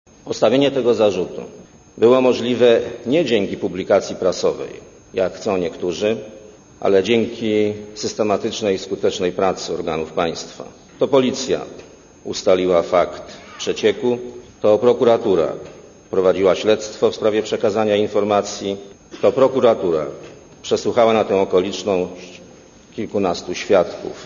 Fragment wystąpienia premiera (96Kb)